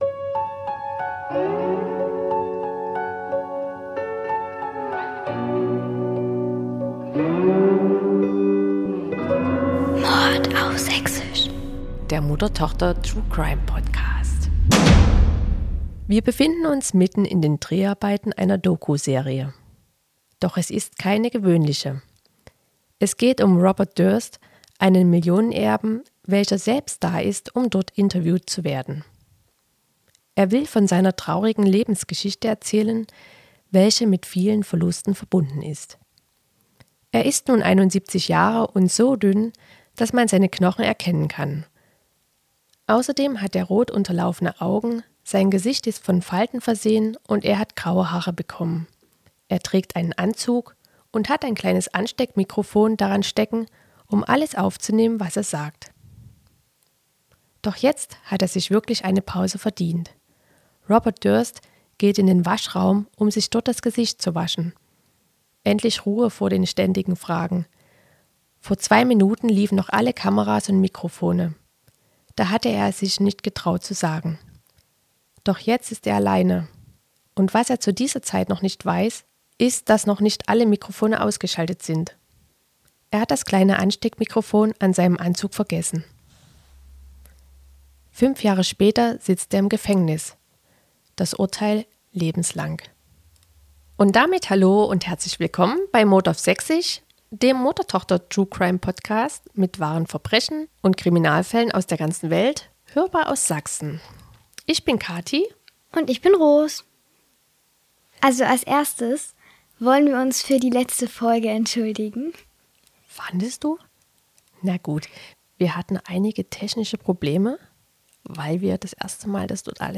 #3 Robert Durst - das Geständnis des Multimillionärs ~ MORD AUF SÄCHSISCH - der Mutter-Tochter-True-Crime-Podcast Podcast